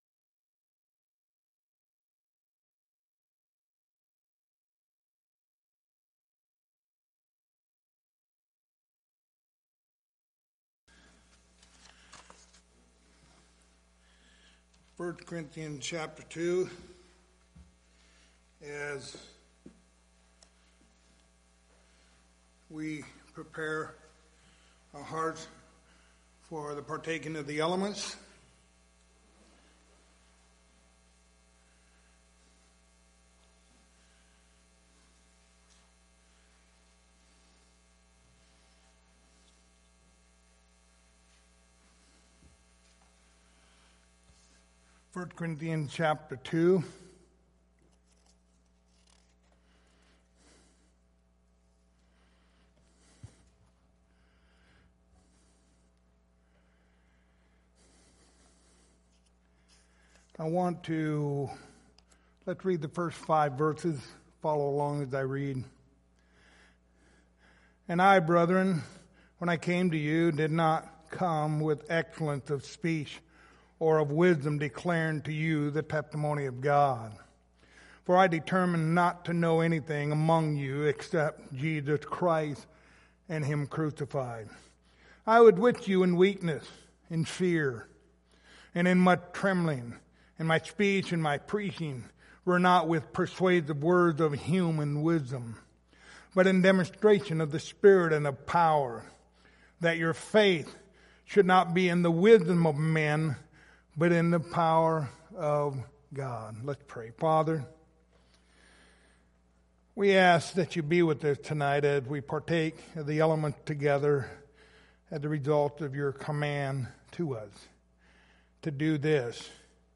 Passage: 1 Corinthians 2:2 Service Type: Lord's Supper